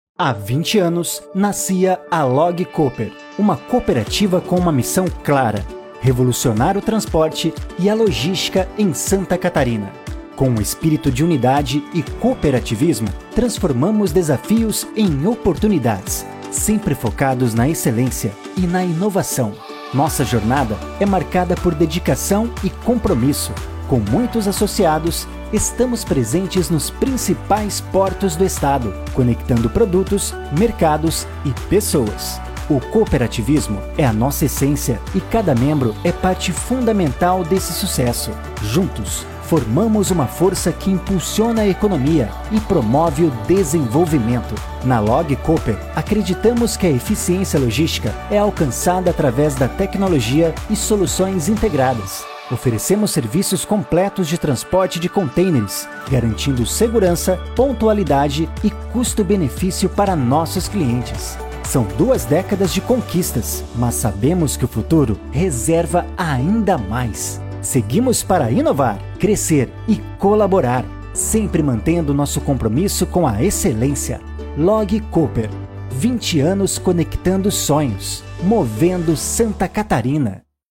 Animada